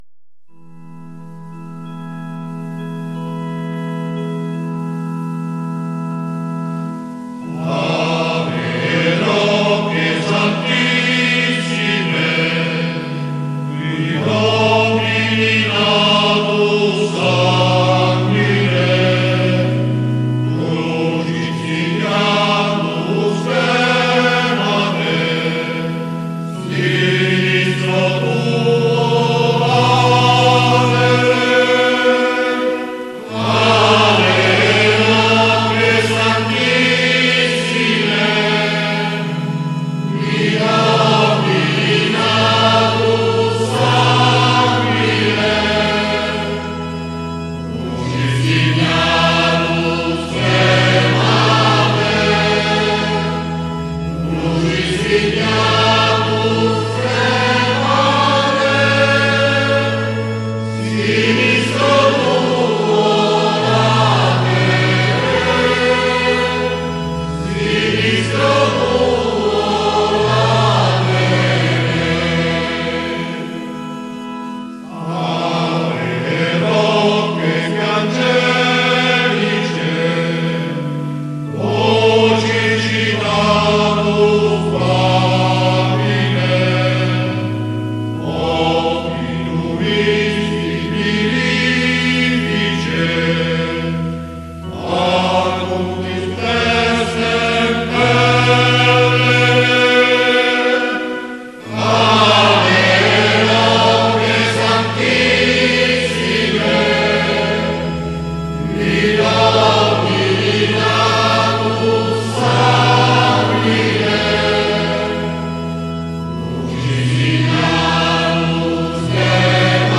Ave Roche Santissime - Scola Cantorum.mp3